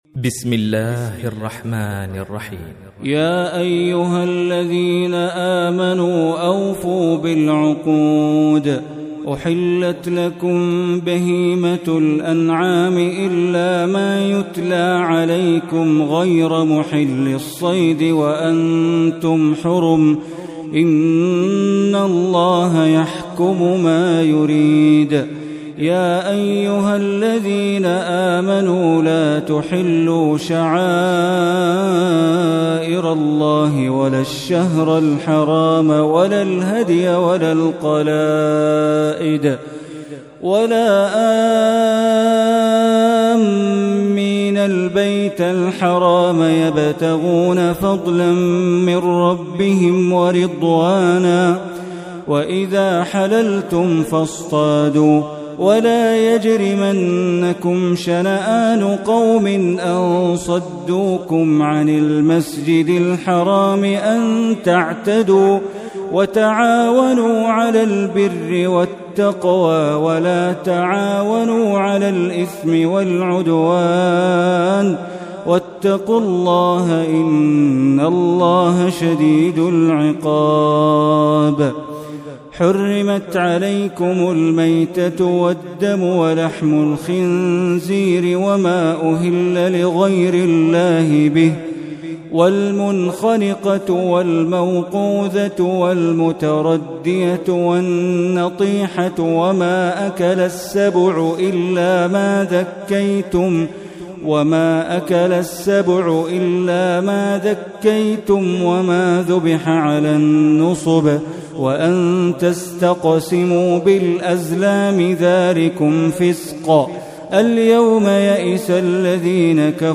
Surah Maidah Recitation by Sheikh Bandar Baleela